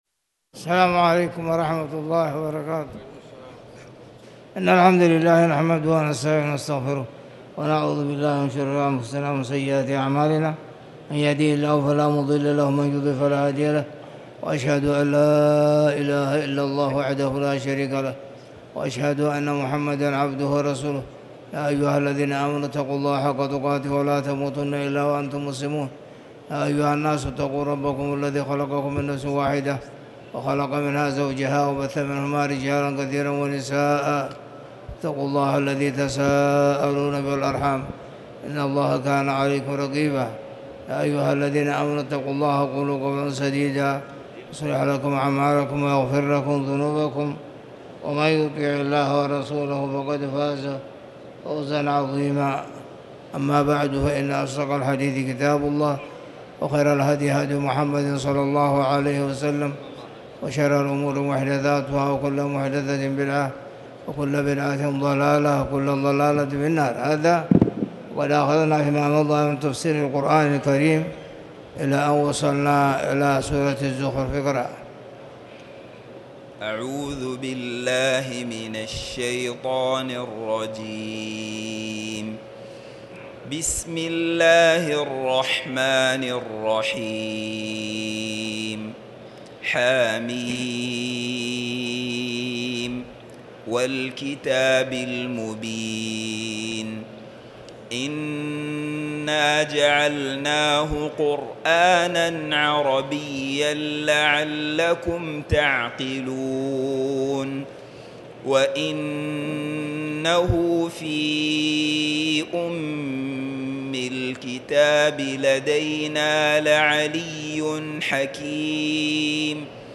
تاريخ النشر ٢ محرم ١٤٤٠ هـ المكان: المسجد الحرام الشيخ